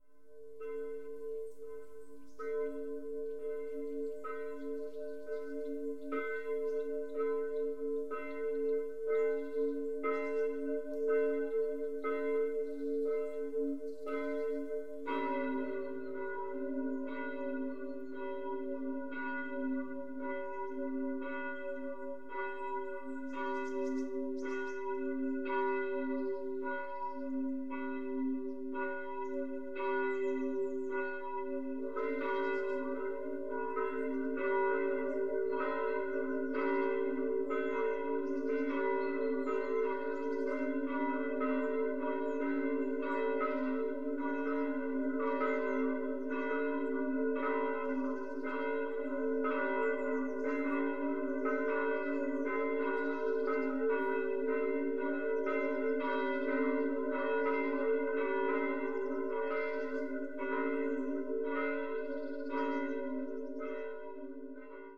klockor_gunnarskog.mp3